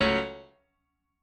admin-leaf-alice-in-misanthrope/piano34_1_008.ogg at a8990f1ad740036f9d250f3aceaad8c816b20b54